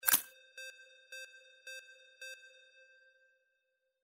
Stereo sound effect - Wav.16 bit/44.1 KHz and Mp3 128 Kbps